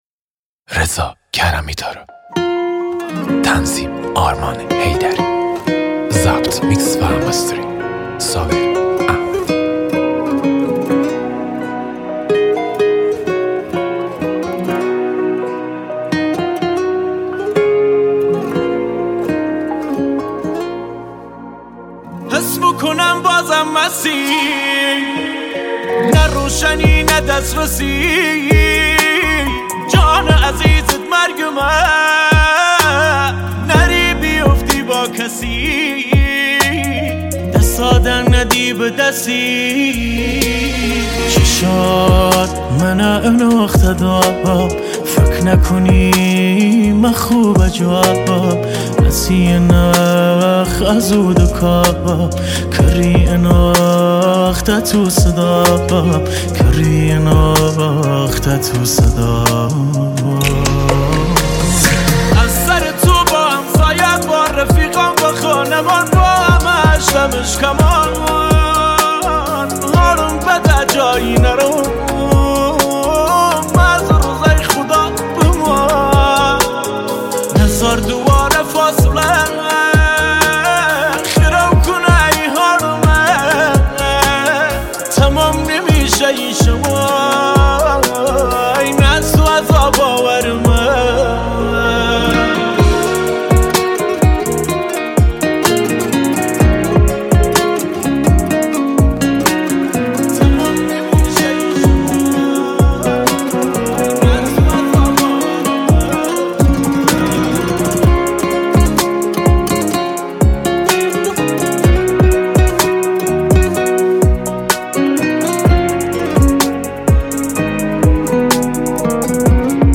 پاپ غمگین عاشقانه کردی عاشقانه غمگین